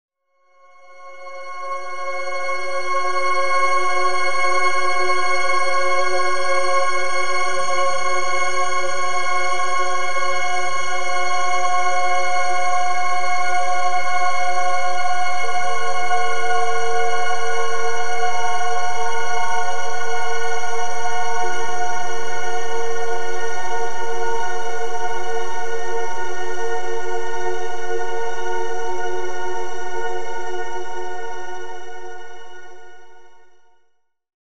C) "Sphärisch"
Abgestimmte spährische Klänge mit überlagertem Ton in 727 Hz